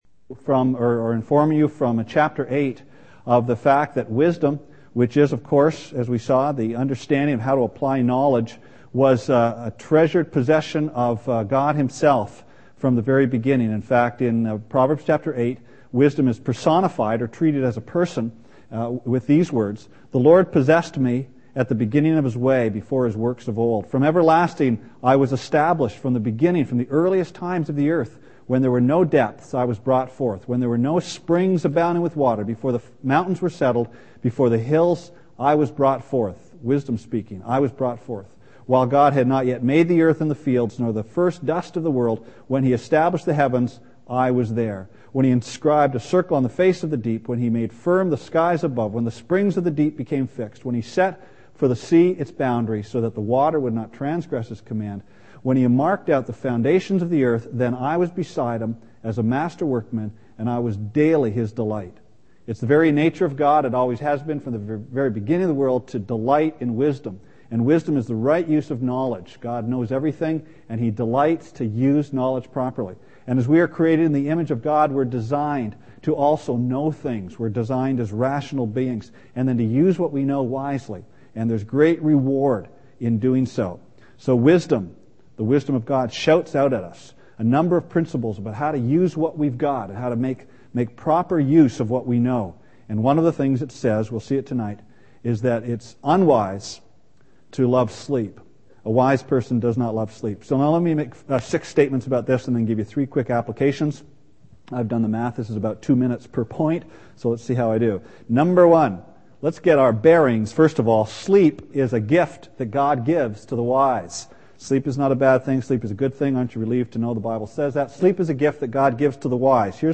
Sermon Archives - West London Alliance Church
Part 3 of a Sunday evening series on Proverbs.